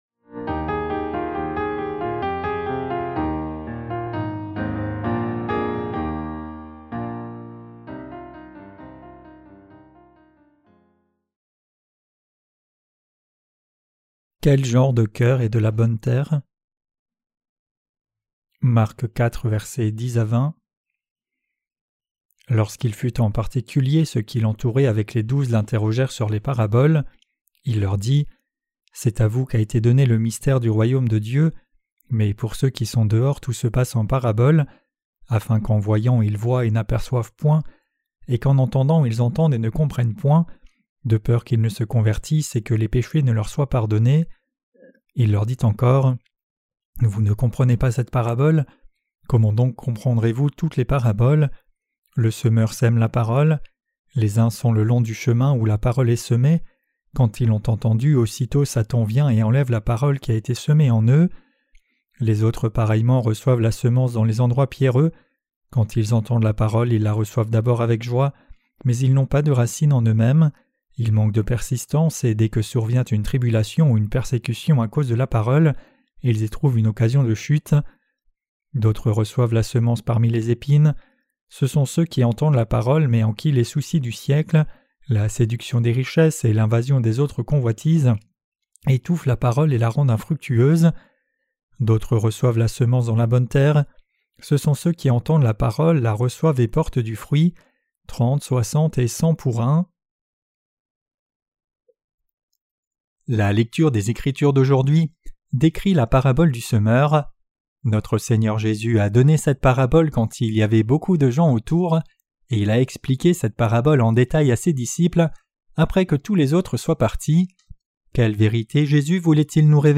Sermons sur l’Evangile de Marc (Ⅰ) - QUE DEVRIONS-NOUS NOUS EFFORCER DE CROIRE ET PRÊCHER? 8.